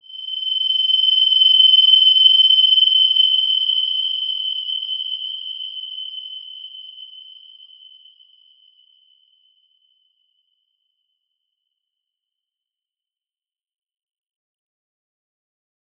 Wide-Dimension-G6-mf.wav